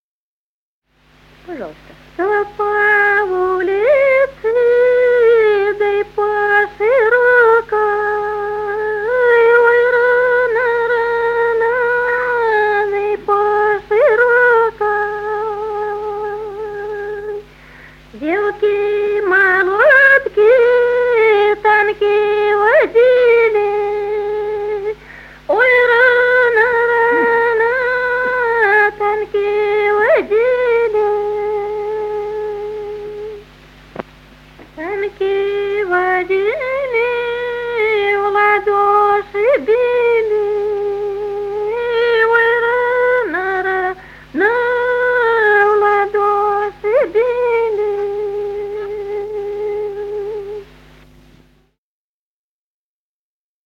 Народные песни Стародубского района «Чтой по улице», духовская таночная.
с. Курковичи.